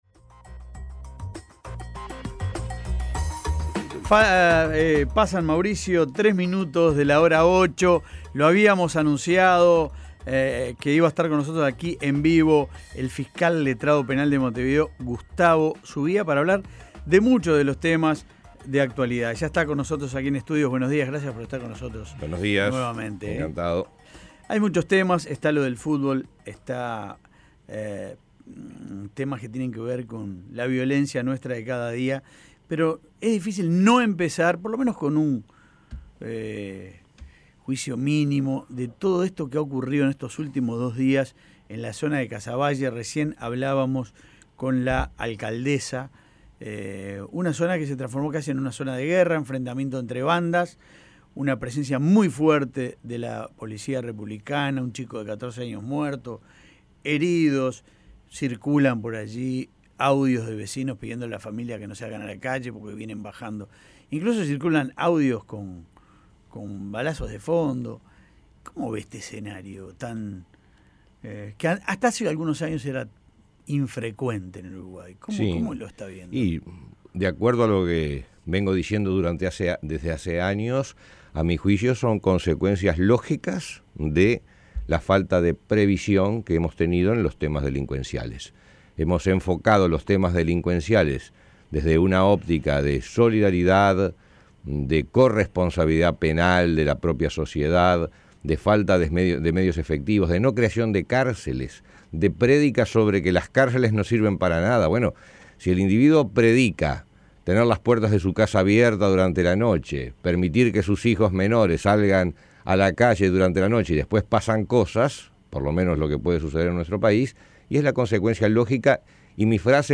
Escuche la entrevista de La Mañana: